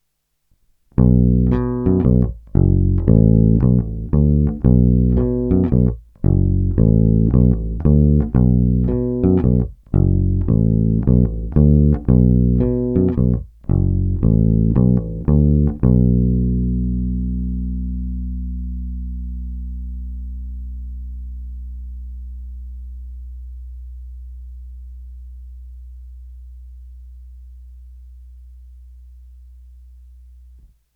Nový snímač je víc vintage, zpět do šedesátkových let.
Není-li uvedeno jinak, nahrávky jsou provedeny rovnou do zvukovky a kromě normalizace ponechány bez úprav.
Hra nad snímačem